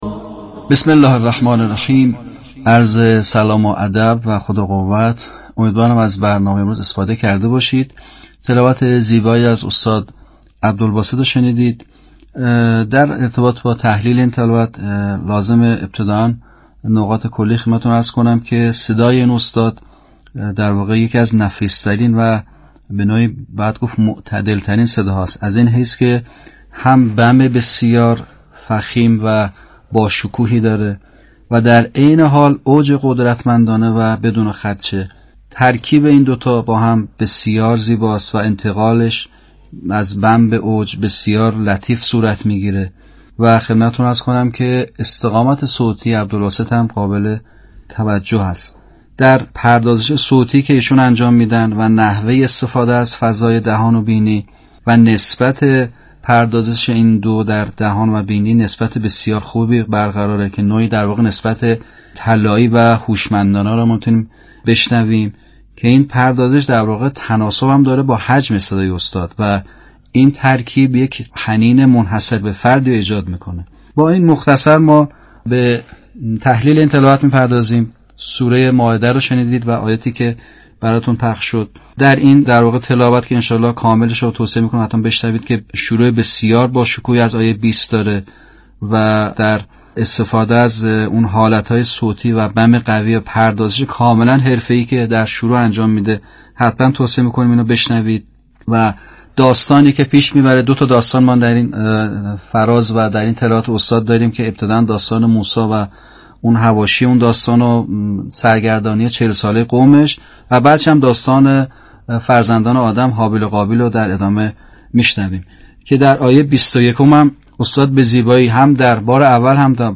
تلاوت آیات سوره مبارکه مائده توسط عبدالباسط
این پردازش با حجم صدای استاد تناسب بسیار خوبی دارد. این ترکیب یک طنین بسیار خوب و منحصربه‌فردی را به اذهان القا می‌کند.
پیشکسوت قرآنی کشور در سخنان خود ادامه بیان کرد: استفاده از نغمات و حالات صوتی متنوع جهت القای معانی و مفهوم آیات از ویژگی‌های بارز مرحوم عبدالباسط است که تبحر فراوانی در این زمینه دارد و ایشان در به‌کارگیری حالات صوتی بسیار استادانه عمل می‌کند.
یادآور می‌شود این تحلیل در برنامه «اکسیر» از شبکه رادیویی قرآن پخش شد.